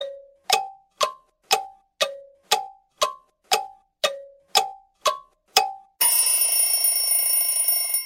Kategorien: Wecktöne